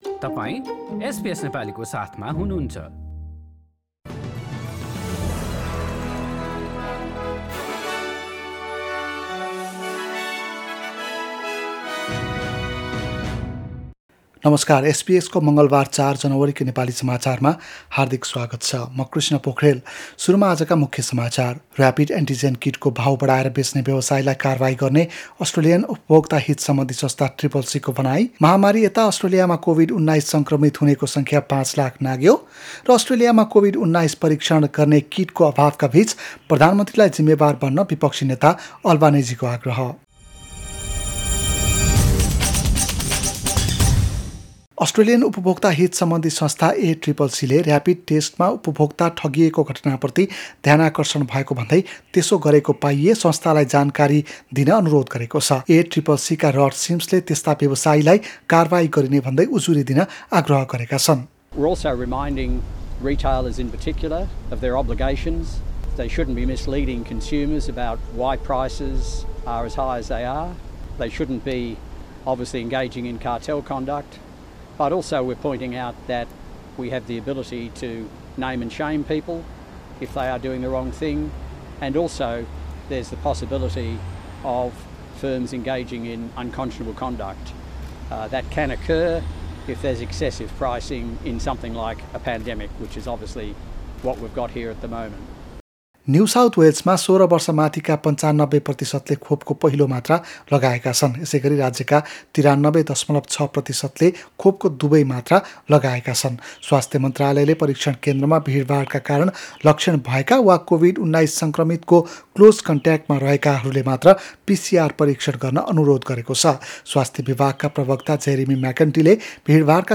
एसबीएस नेपाली अस्ट्रेलिया समाचार: मंगलबार ४ जनवरी २०२२